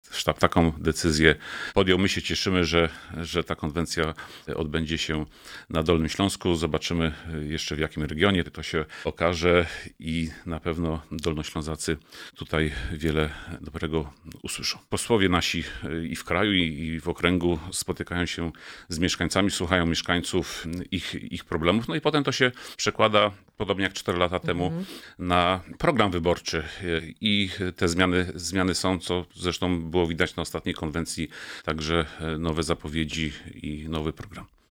-Cieszymy się , że spotkanie odbędzie się w naszym regionie, mówi Damian Mrozek  Wiceprzewodniczący Klubu w Sejmiku Województwa Dolnośląskiego z ramienia  Prawo i Sprawiedliwość.